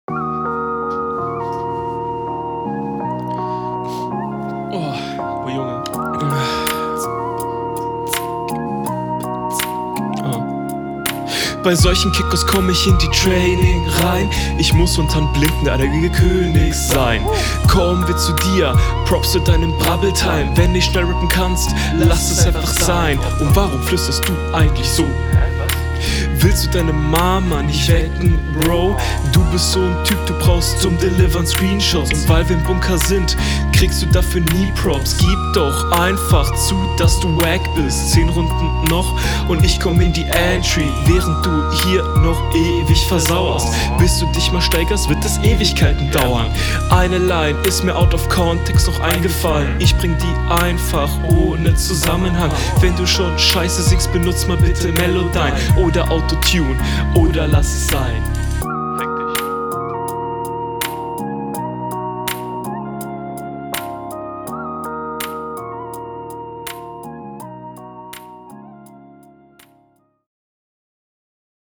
Sehr Chilliger Beat hast du da gewählt Flow passt gut auf den beat aber immer …
Die Runde wirkt wie die vorherige eher gesprochen, als gerappt.
Auch hier wieder sehr holpriger flow.